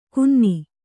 ♪ kunni